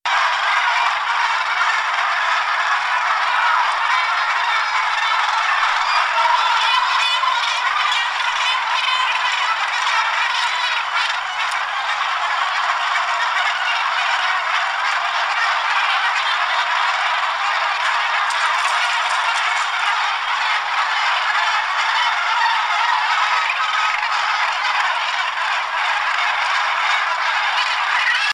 Morus capensis - Piquero del cabo
piquerodelcabo.wav